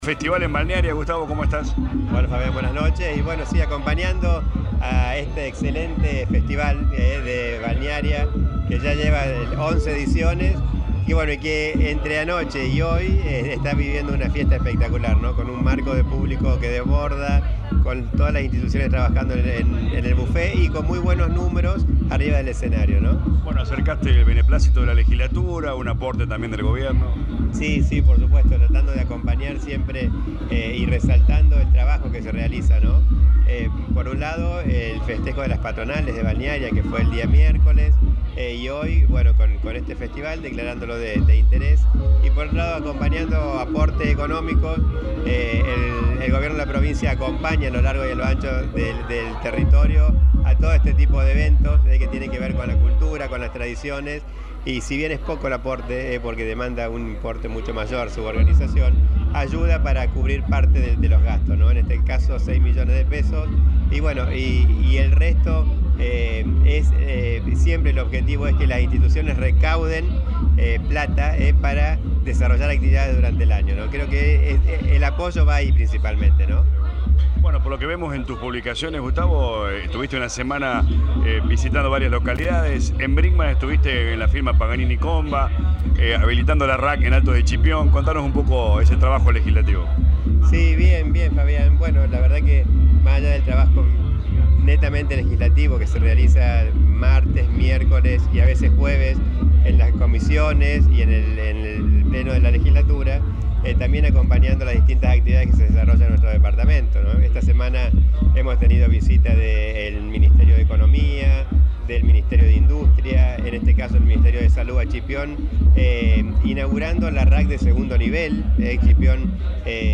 Lo confirmó el legislador departamental Dr. Gustavo Tevez en diálogo con LA RADIO 102.9 FM.